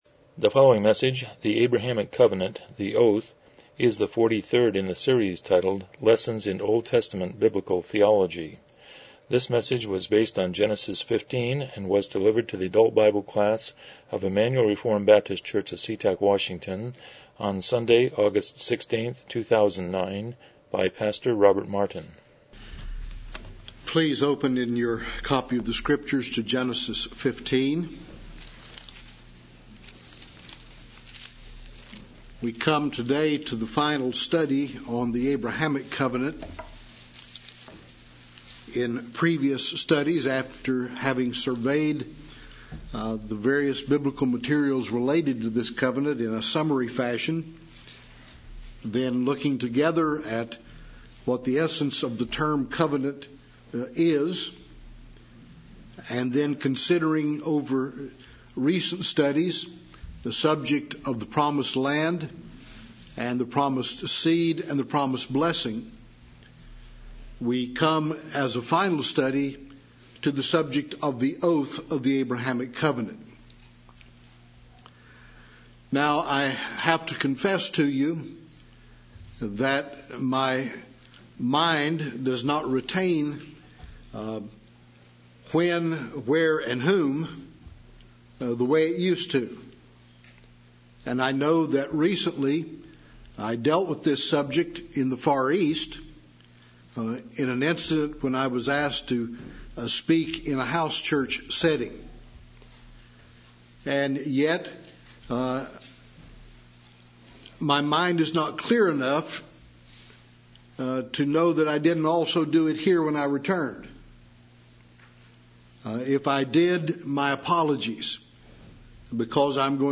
Lessons in OT Biblical Theology Service Type: Sunday School « 70 Chapter 12